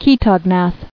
[chae·tog·nath]